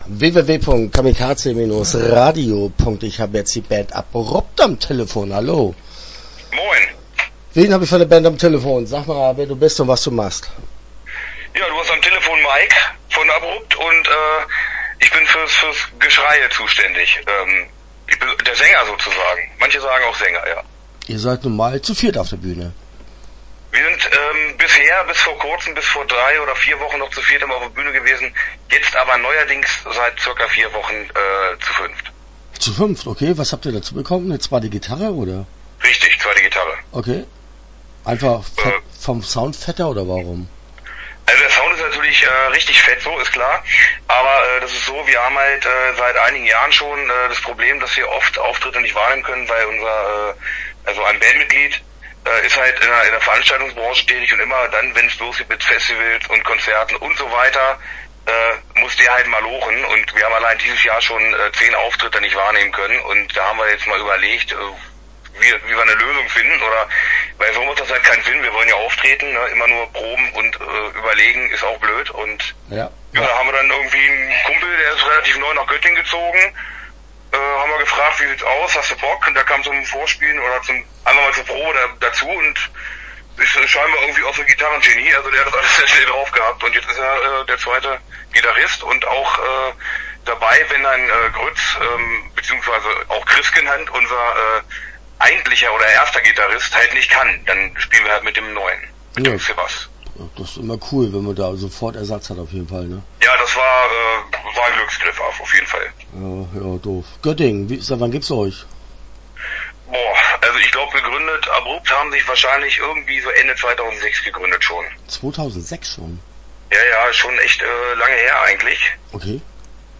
ABRUPT - Interview Teil 1 (10:10)